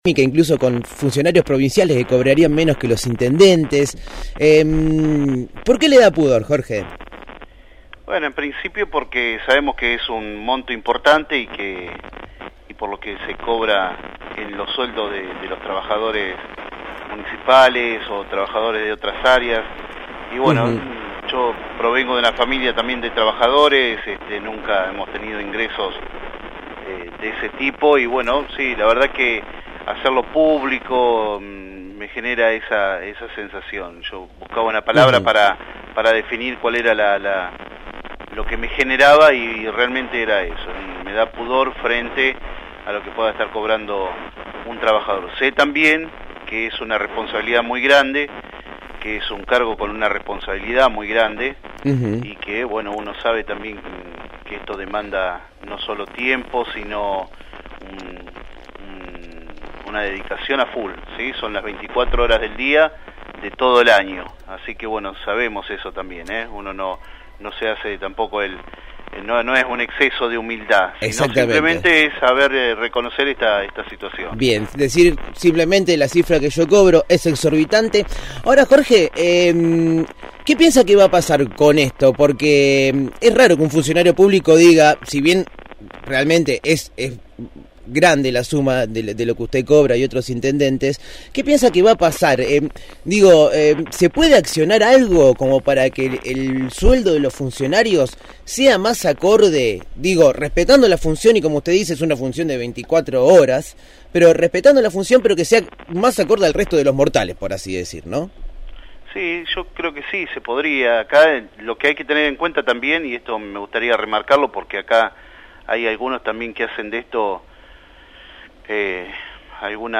Jorge Nedela, intendente de Berisso, dialogó con el equipo de «El hormiguero» sobre la cifra de su recibo de sueldo, que roza los 160 mil pesos, y sobre los primeros meses de gestión al frente de la localidad vecina.